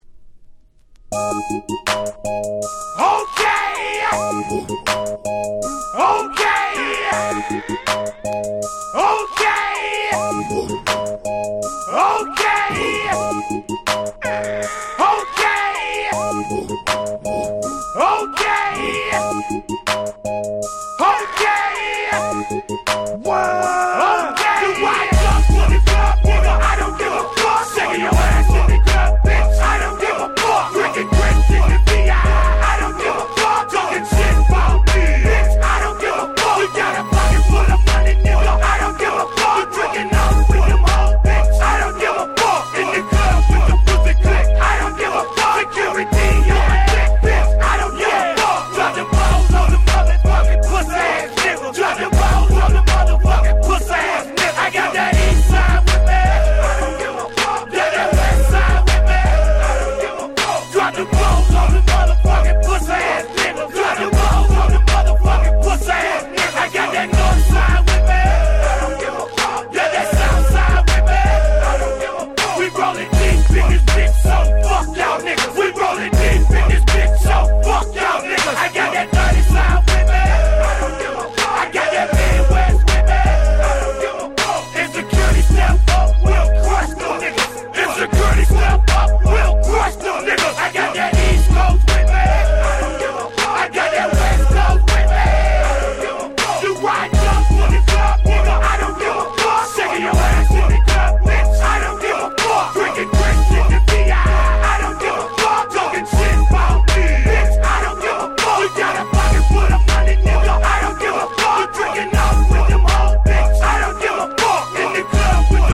02' Smash Hit Southern Hip Hop !!
Dirty South Classics !!
イントロから相変わらず「オッケィー！！」連発のサウスクラシック！！